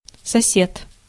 Ääntäminen
GenAm: IPA : /ˈneɪ.bɚ/ US : IPA : [ˈneɪ.bɚ] UK RP : IPA : /ˈneɪ.bə/